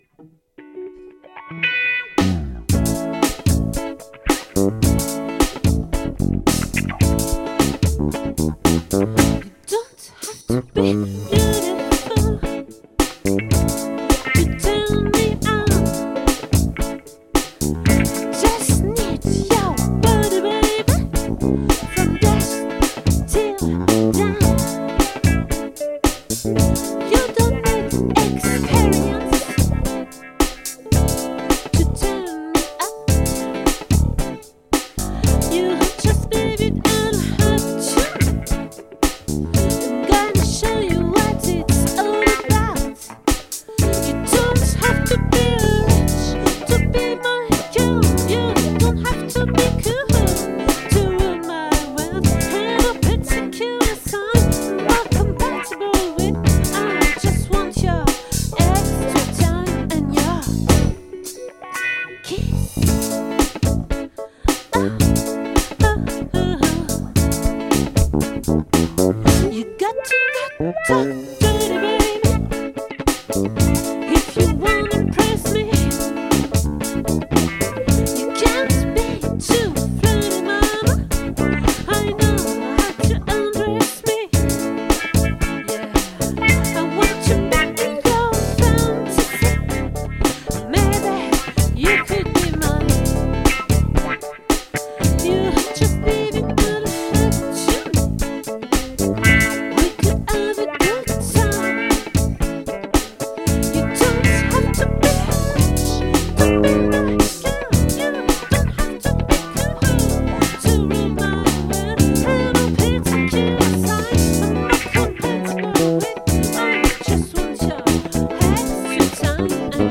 🏠 Accueil Repetitions Records_2023_02_15